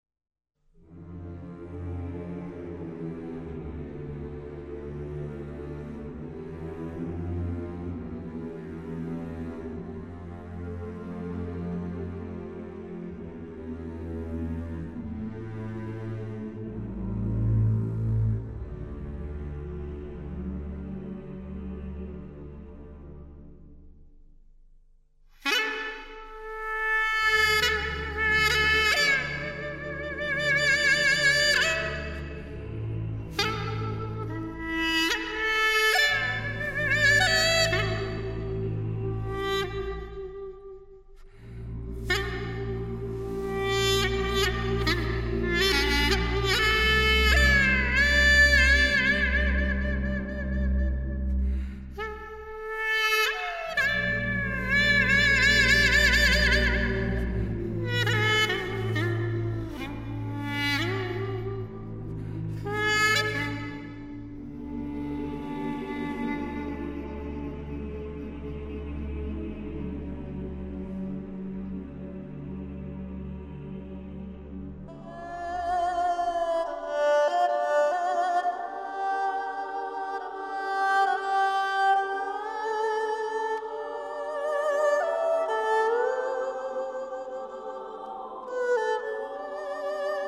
在中國中央電視臺的 480 平米類比錄音棚運用頂級 DSD 錄音設備錄製